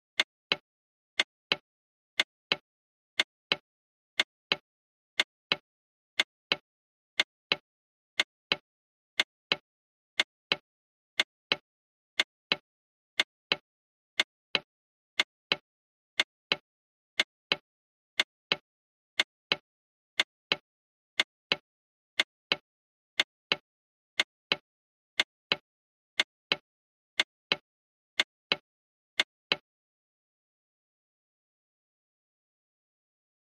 Clock Ticks, Antique Clock, Close Up Perspective, Very Clean .